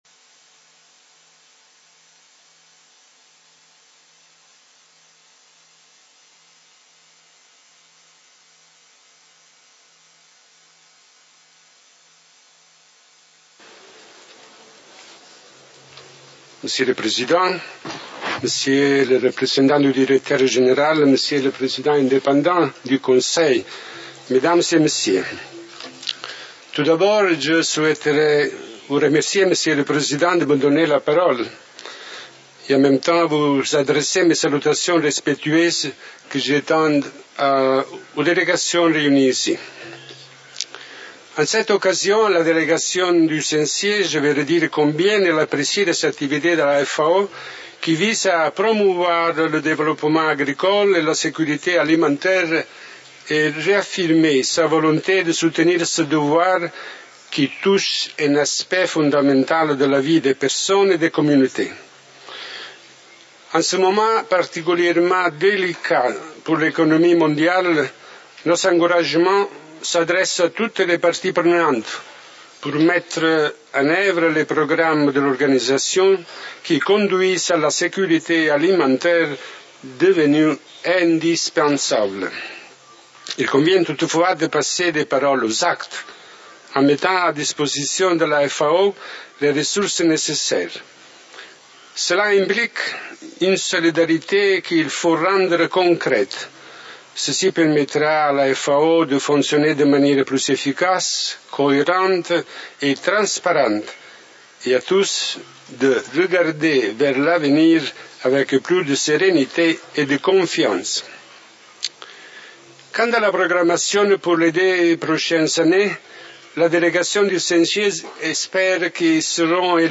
FAO Conference
Statements by Heads of Delegations under Item 9:
Son Excellence Révérendissime l'Archevêque Luigi Travaglino Nonce Apostolique Observateur permanent du Saint-Siège auprès de la FAO